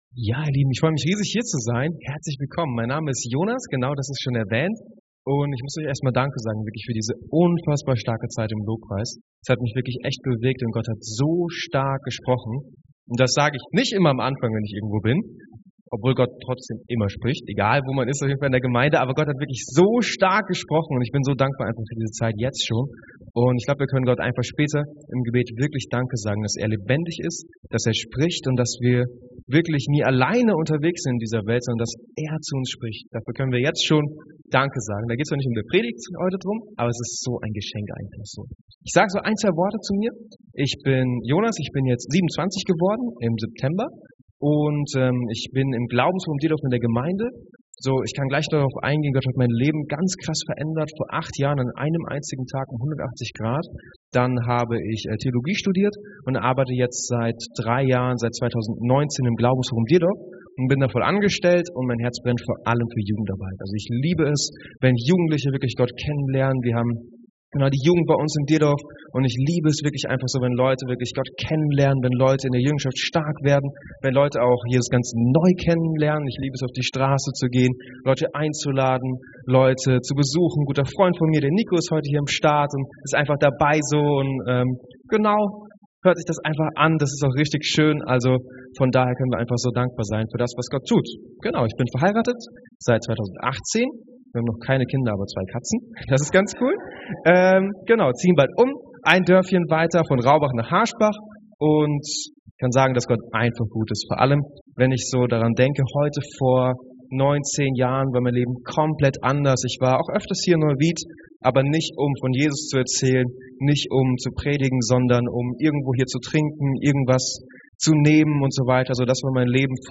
Bibeltext zur Predigt: Epheser 4,15-16